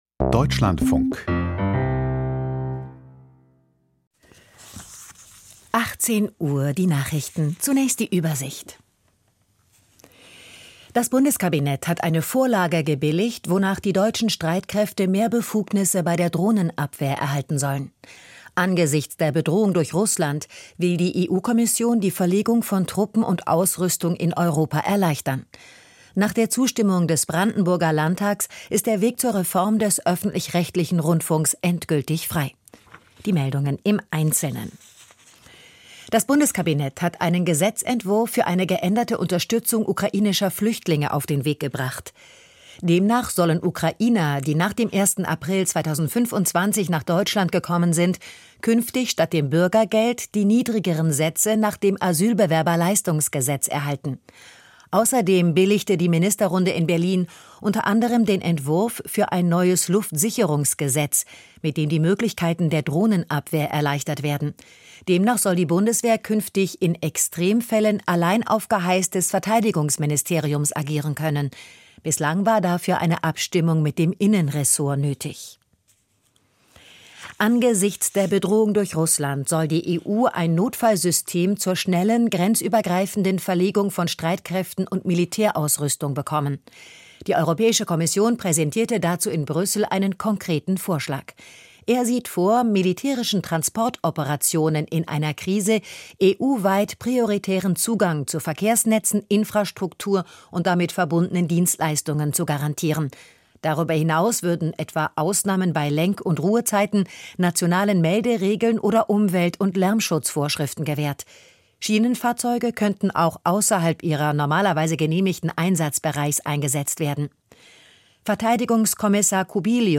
Die Nachrichten vom 19.11.2025, 18:00 Uhr